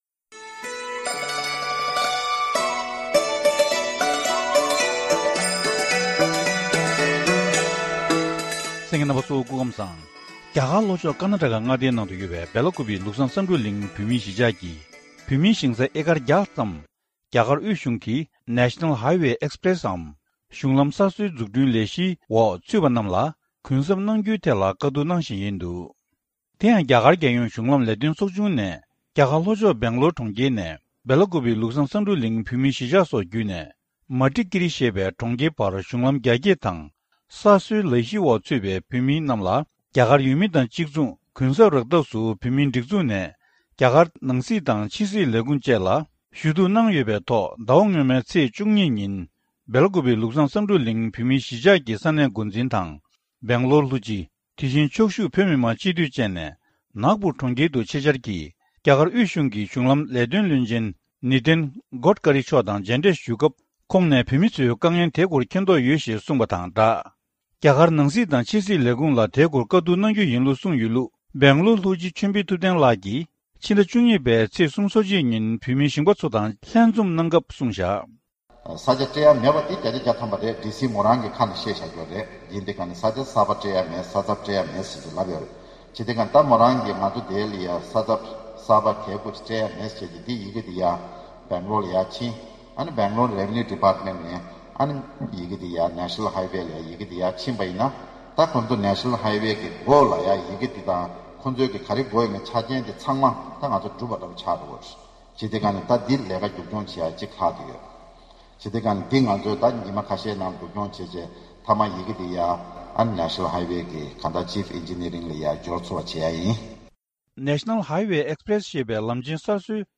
ཞིབ་གསལ་བཀའ་འདྲི་ཞུས་ནས་ཕྱོགས་སྒྲིག་ཞུས་པ་ཞིག་གསན་རོགས་གནང་།།